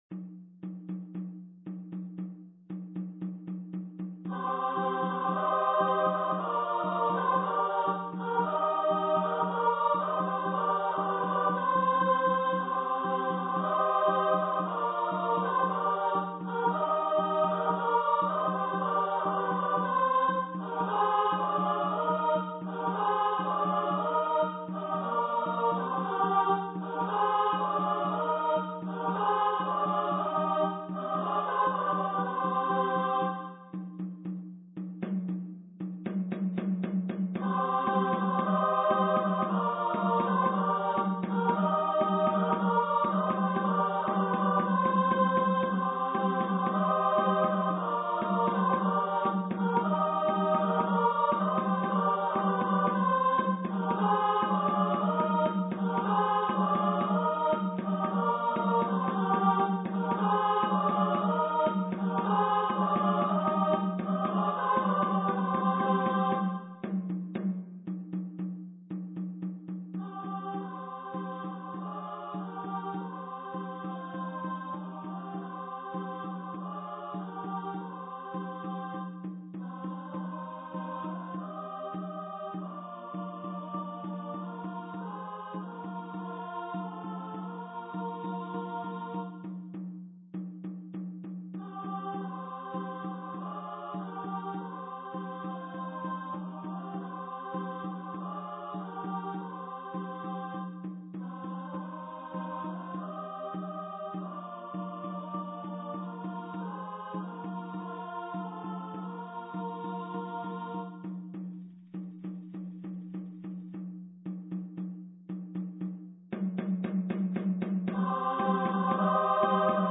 for female voice choir
unaccompanied female choir
tambour and tambourine accompaniment